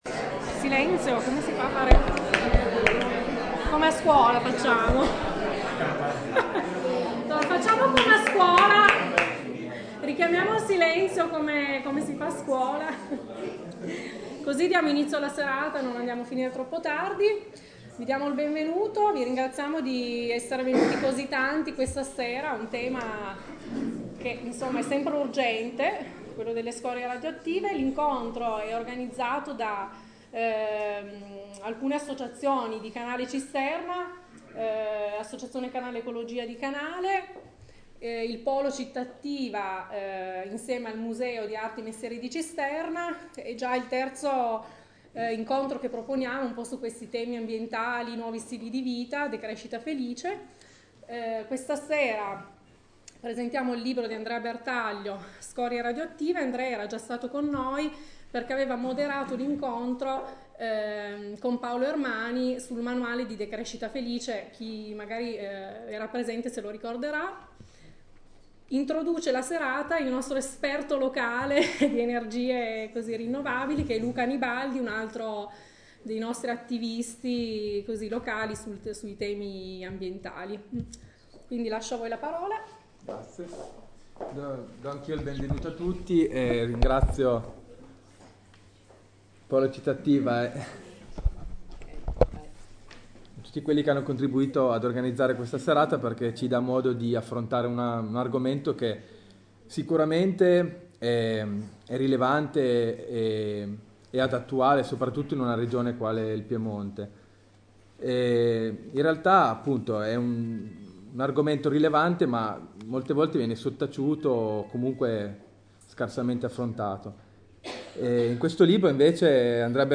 13^ INCONTRO DEL POLO CITTATTIVA PER L’ASTIGIANO E L’ALBESE PER L’A.S. ‘12/’13